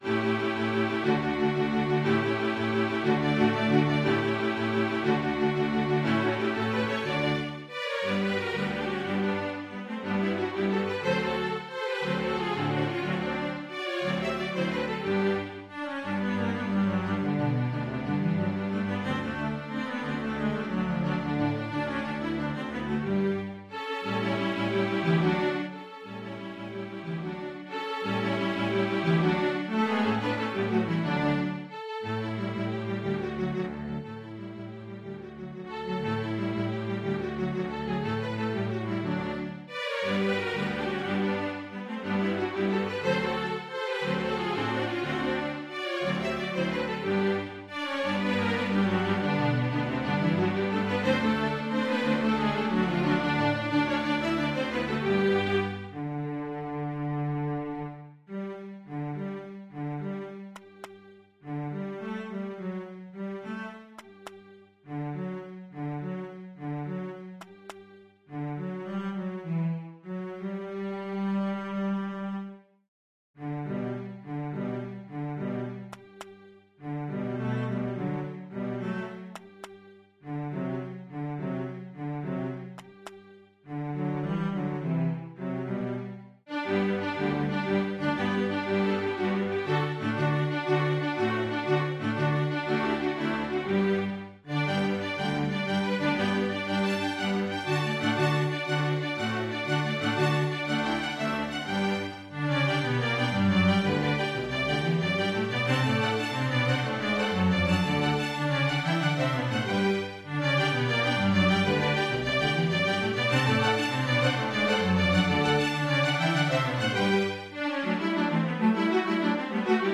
String Orchestra
Traditional Mexican Folk Song